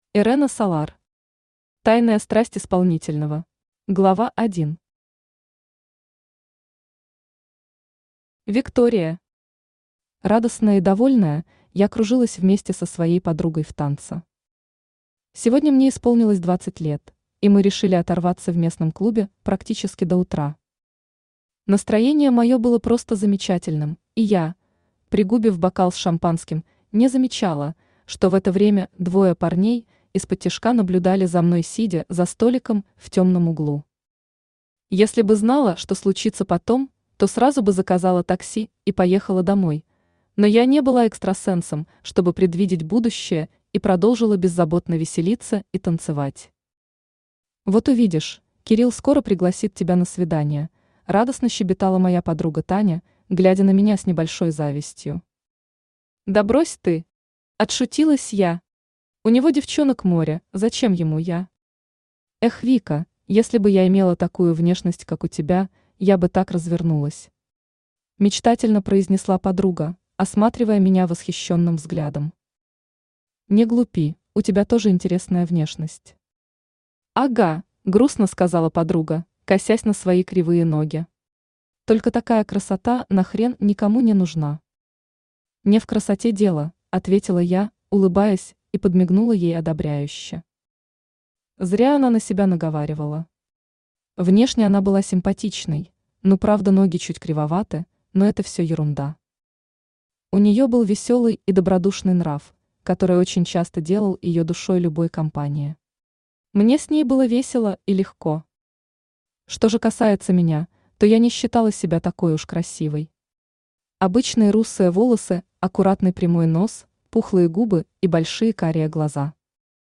Аудиокнига Тайная страсть исполнительного | Библиотека аудиокниг
Aудиокнига Тайная страсть исполнительного Автор Ирэна Солар Читает аудиокнигу Авточтец ЛитРес.